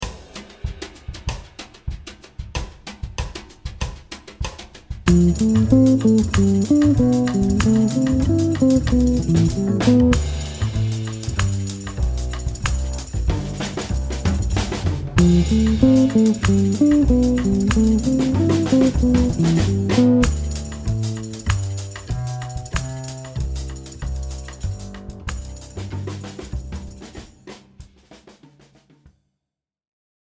Triade avec saut vers la Quinte et descente vers la Fondamentale.
Phrase 07 – Cadence ii . V7 . I en Majeur
Les motifs de Triade sont choisis sur des accords substitutifs à la progression originale.
Phrase-07-ii-V7-I-en-C-Maj.mp3